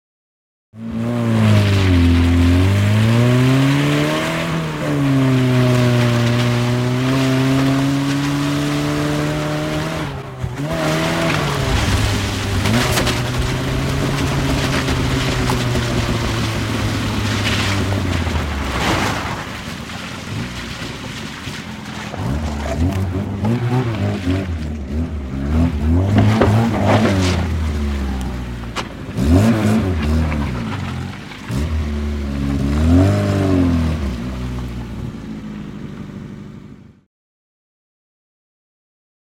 Лада свернула в кювет